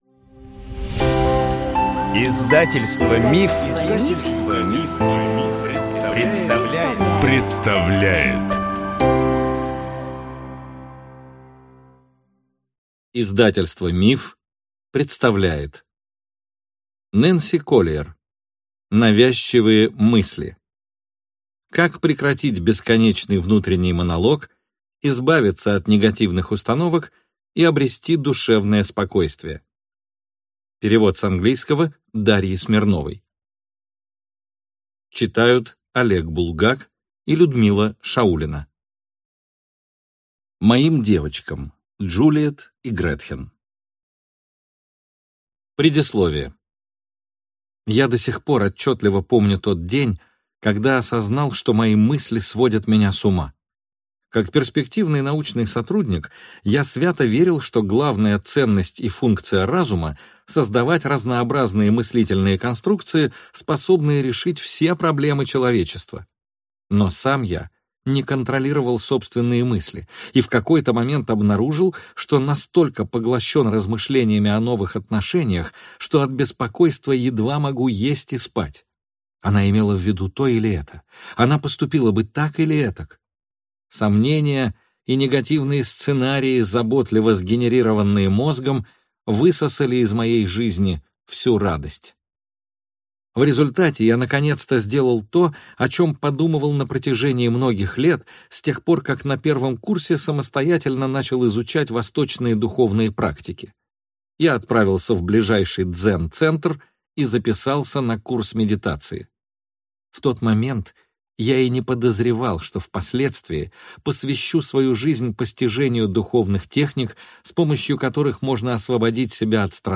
Аудиокнига Навязчивые мысли. Как прекратить бесконечный внутренний монолог, избавиться от негативных установок и обрести душевное спокойствие | Библиотека аудиокниг